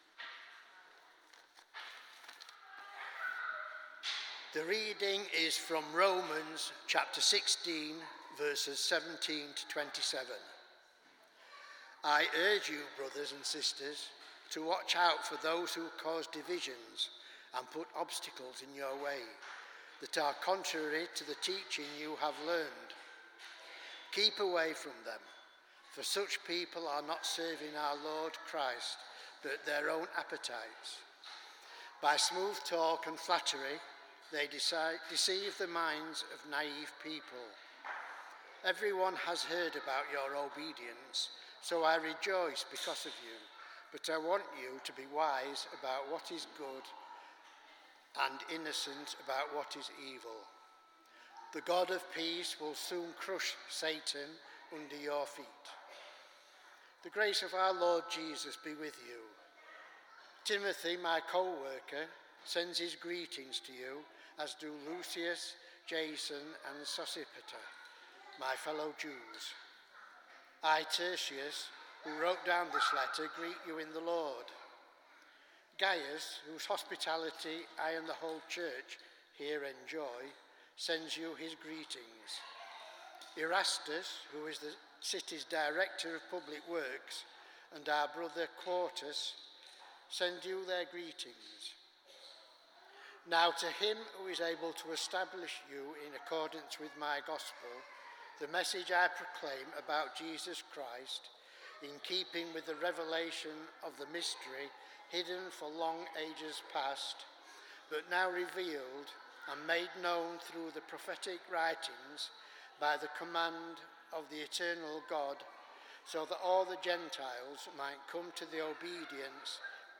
Passage: Romans 16: 17-27 Service Type: Sunday Morning